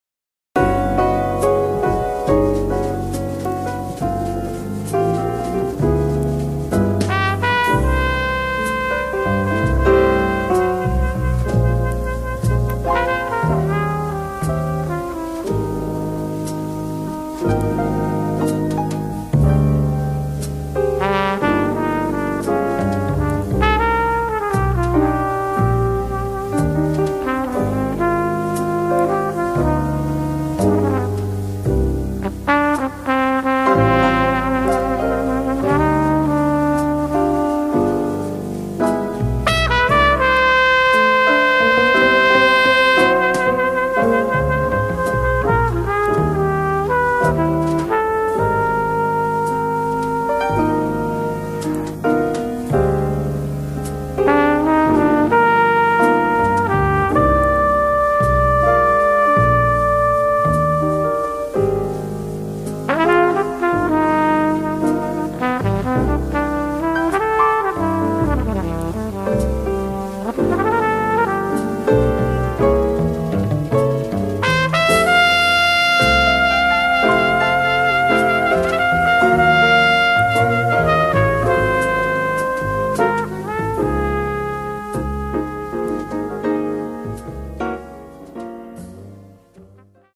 trumpet & flugelhorn • With
drums
tenor saxophone
piano
bass